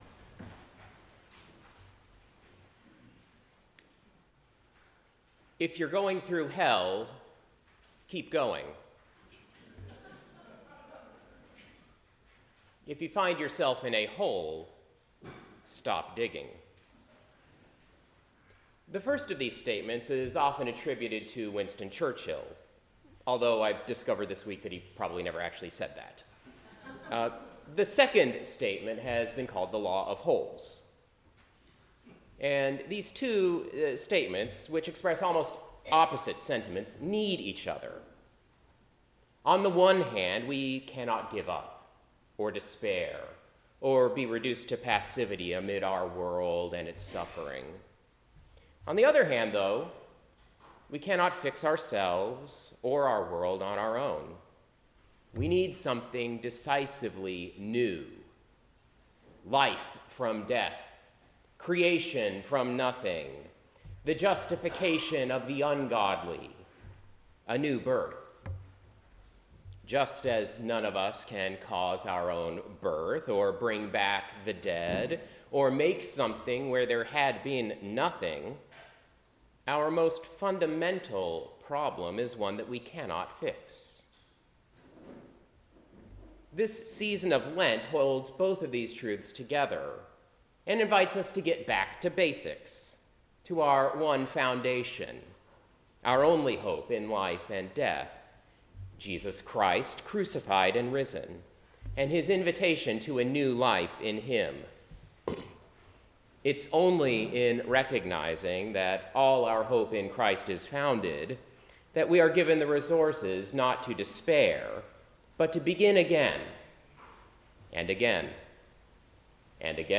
Sermon-Second-Sunday-in-Lent-March-1-2026.mp3